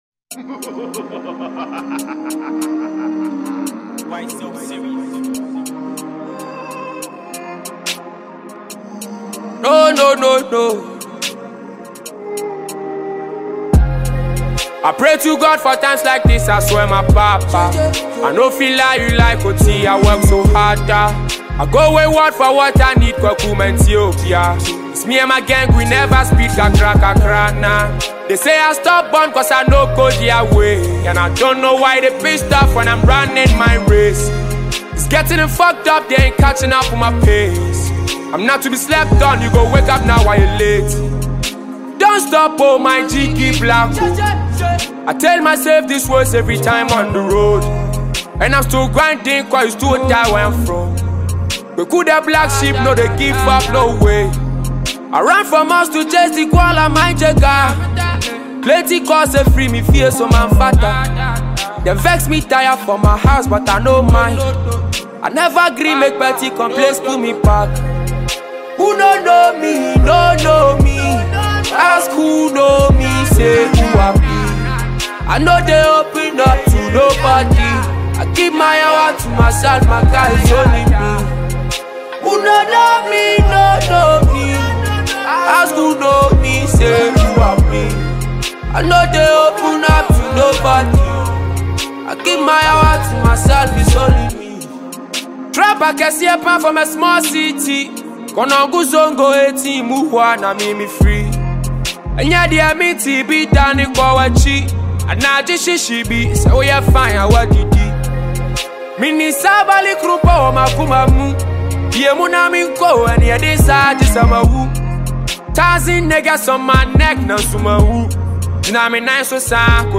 Multi-capable Ghanaian singer and tune writer